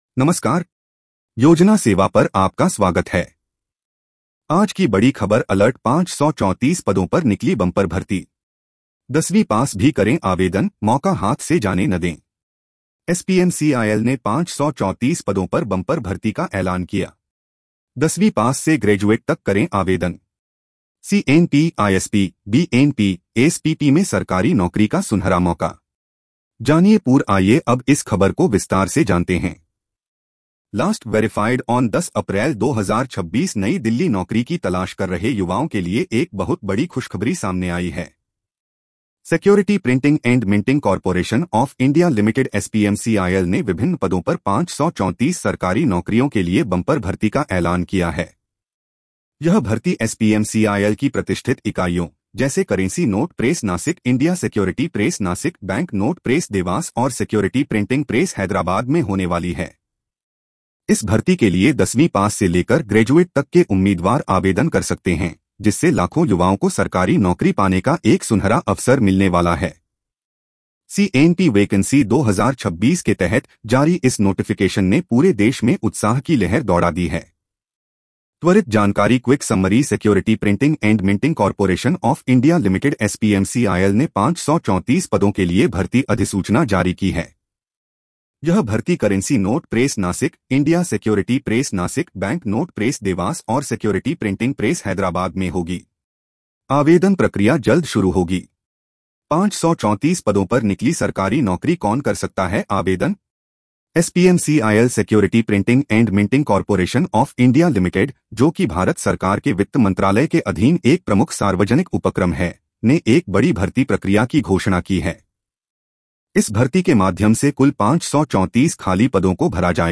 News Audio Summary
🎧 इस खबर को सुनें (AI Audio):